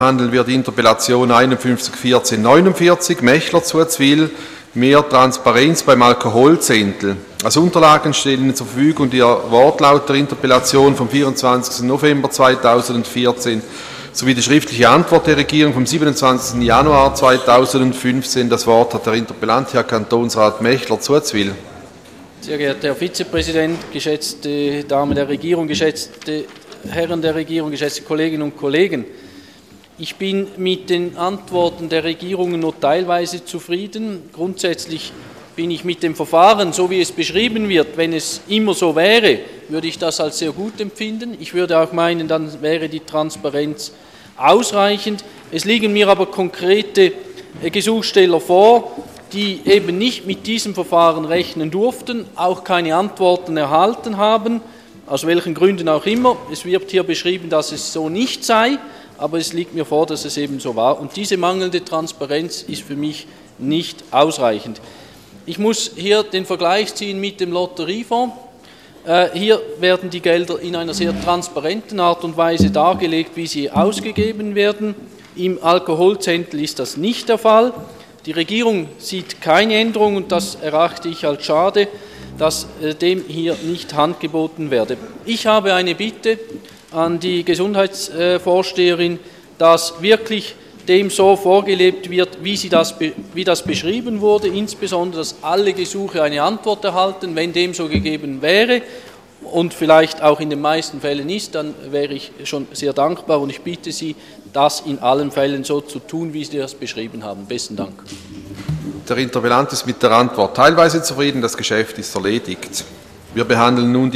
25.2.2015Wortmeldung
Session des Kantonsrates vom 23. bis 25. Februar 2015